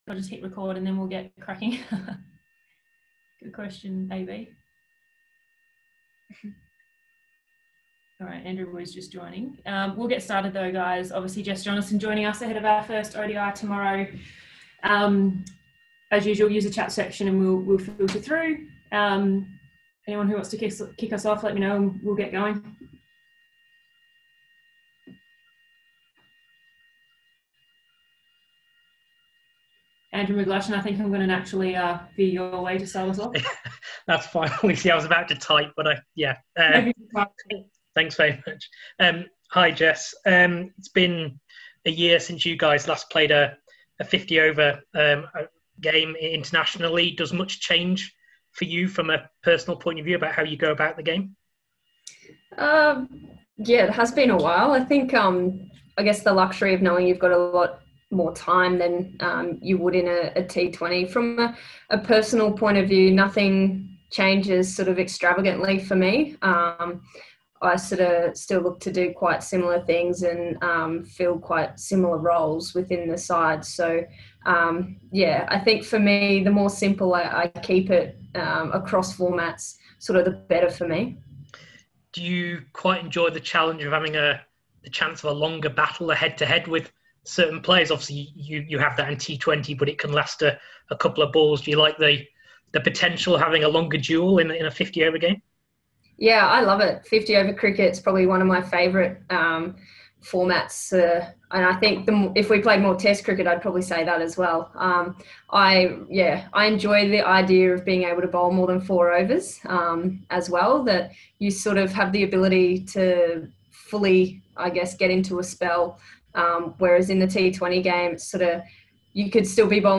Australia spinner and No.1 ranked ODI bowler Jess Jonassen spoke to media today ahead of the first match of the Commonwealth Bank Women's ODI series against New Zealand tomorrow.